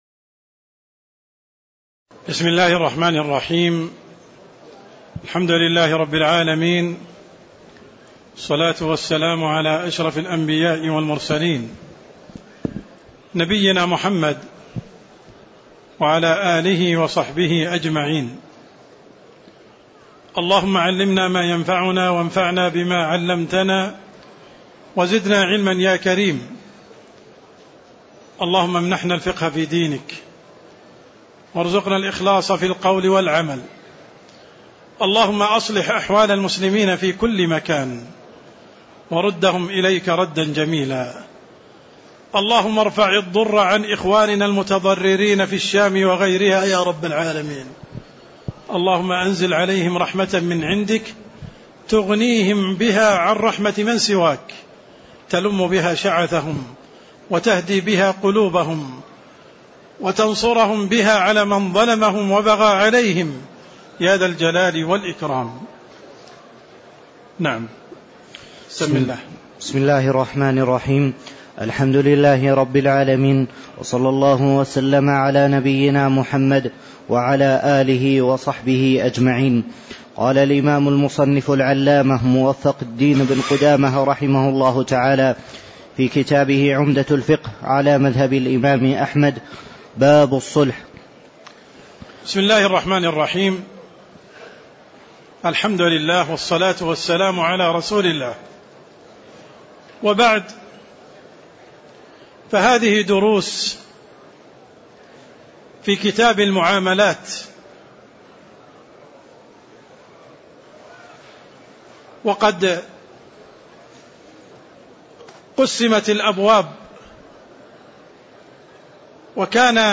تاريخ النشر ٢٧ رجب ١٤٣٧ هـ المكان: المسجد النبوي الشيخ: عبدالرحمن السند عبدالرحمن السند أحكام الصلح والوكالة (01)